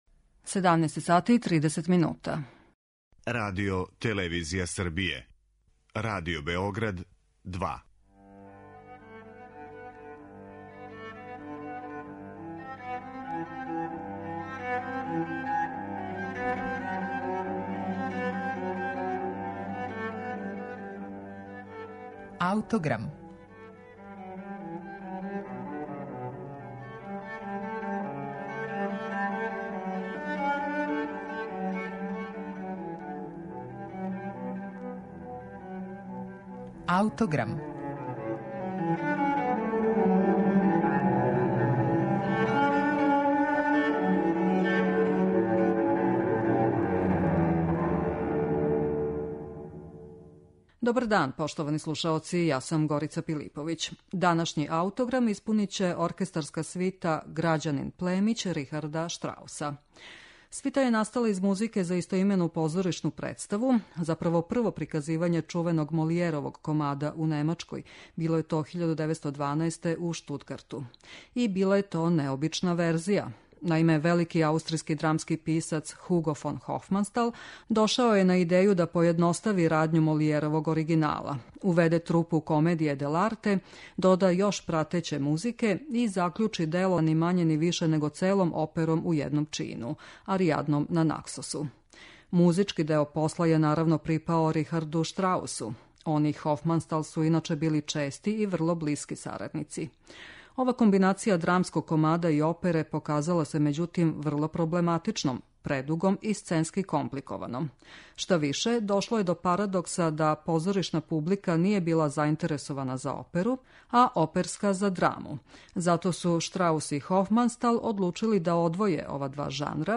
Данашњи Аутограм испуниће оркестарска свита „Грађанин племић" Рихарда Штрауса, која је настала из музике за истоимену позоришну представу.